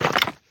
Minecraft Version Minecraft Version 1.21.5 Latest Release | Latest Snapshot 1.21.5 / assets / minecraft / sounds / mob / wither_skeleton / step2.ogg Compare With Compare With Latest Release | Latest Snapshot
step2.ogg